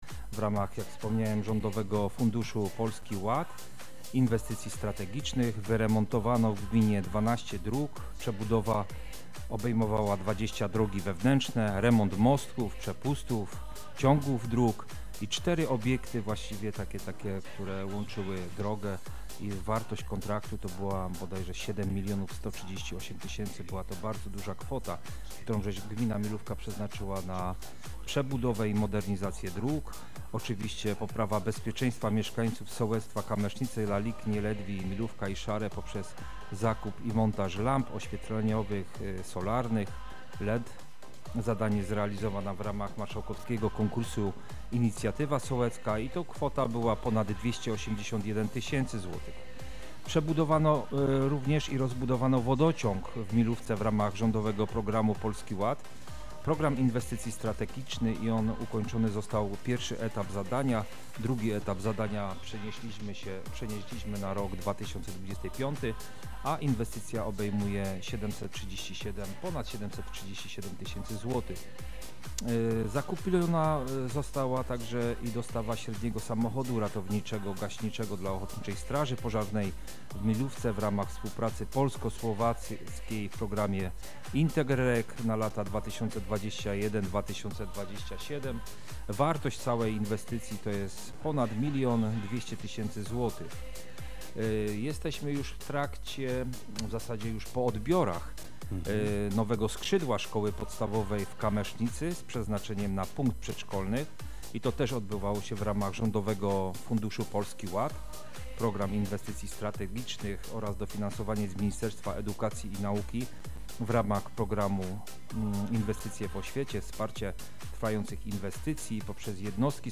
Dziś w godzinach porannych odbyło się spotkanie z wójtem gminy Milówka, Krzysztofem Kamińskim. W miłej atmosferze dyskutowaliśmy o kluczowych kwestiach dotyczących rozwoju regionu oraz bieżących wyzwaniach, które mają wpływ na życie mieszkańców.